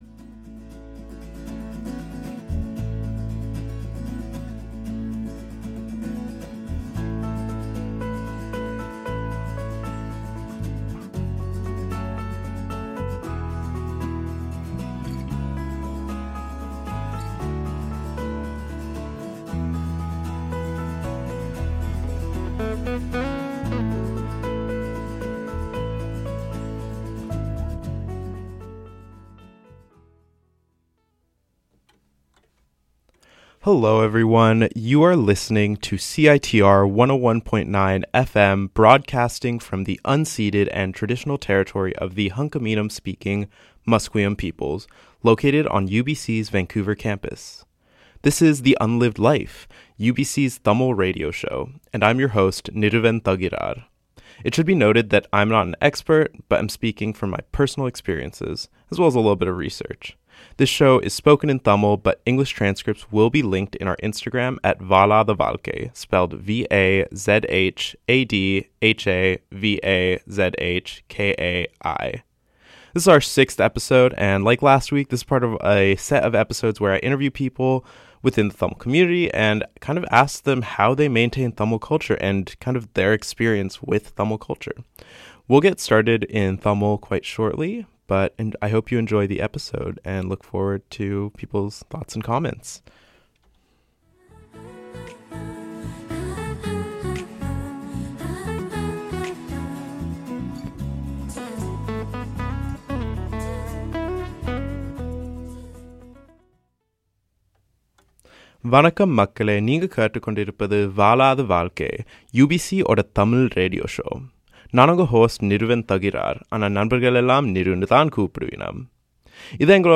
This is the second part in an ongoing series of interviews where Tamil guests discuss their experiences with Tamil culture.